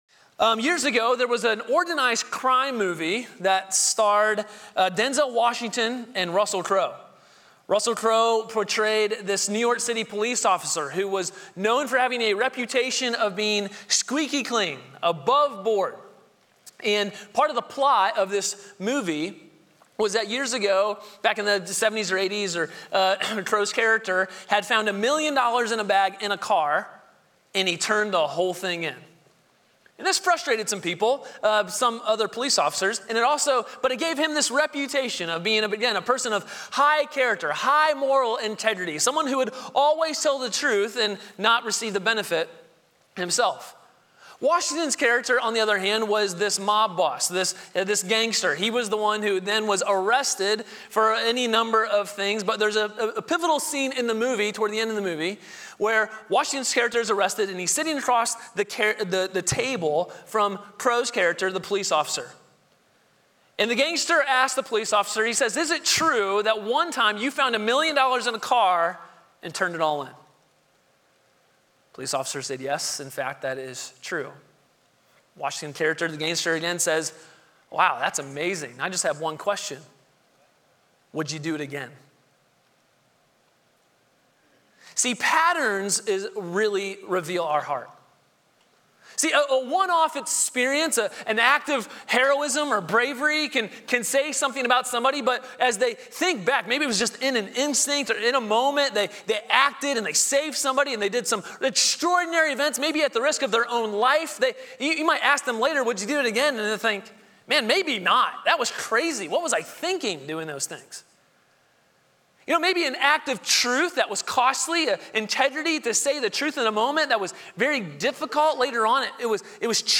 Sermons • Grace Polaris Church